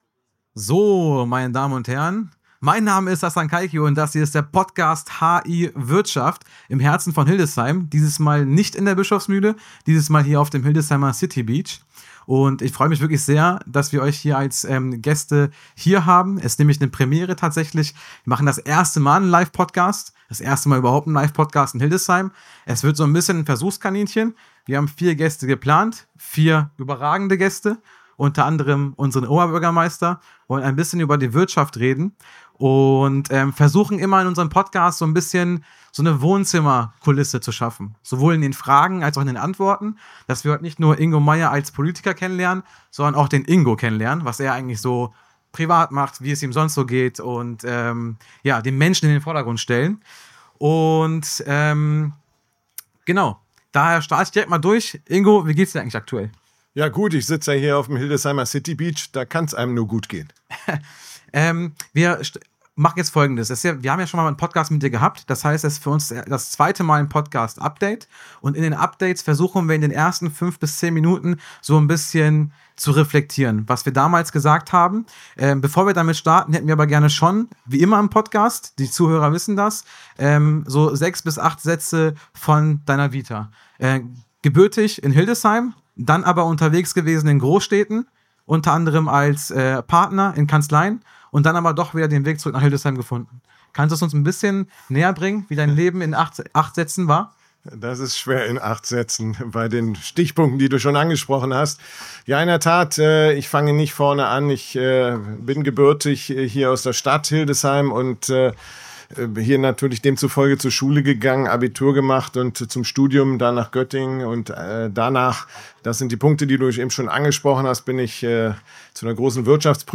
Beschreibung vor 8 Monaten PREMIERE: Die erste Live-Podcast-Episode direkt vom Hildesheimer City Beach! Wir durften ein Pod-Update unter freiem Himmel aufnehmen. Mit dabei: Hildesheims Oberbürgermeister Dr. Ingo Meyer. Gemeinsam sprechen wir über seinen persönlichen Werdegang, die wirtschaftliche Zukunft Hildesheims und die Herausforderungen von Großprojekten wie dem neuen Autobahnanschluss.